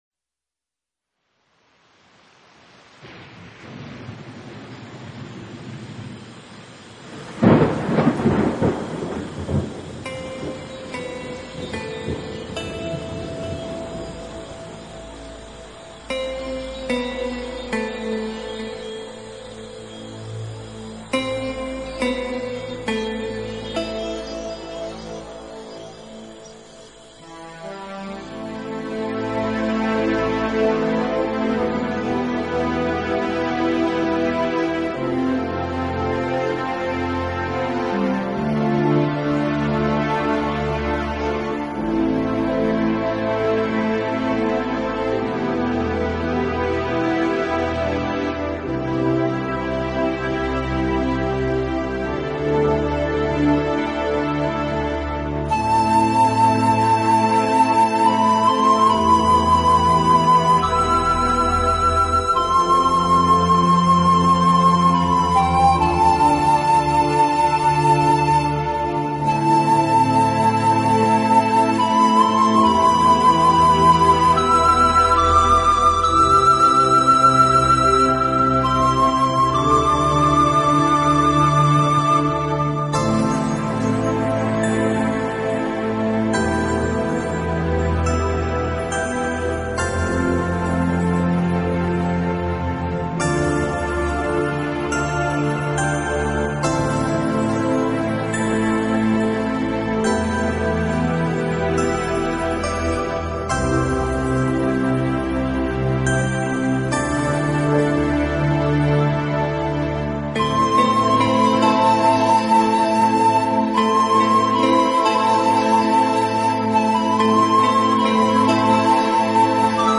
流水、雀鸟之声，能镇静人的情绪，松弛我们的身心，而且给人一种返回
从其不落俗套编曲，精简的乐器配置，使每首曲子都呈现出清新的自然气息。